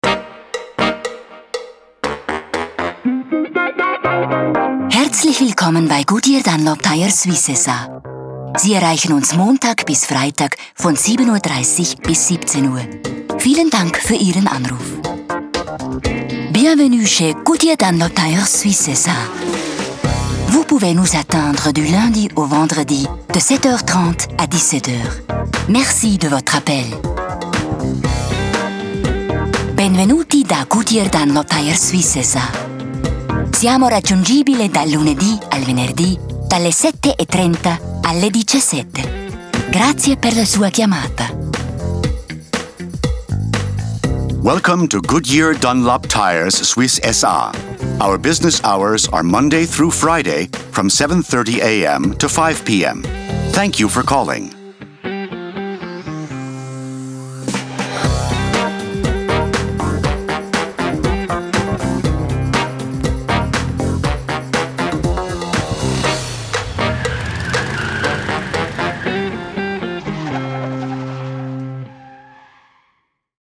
Sprecherin
Goodyear/Dunlop Telefonansage CH/FR/ITA
Goodyear Dunlop Telefonansage.mp3